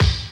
Index of /90_sSampleCDs/Best Service Dance Mega Drums/10 KIT D+B 2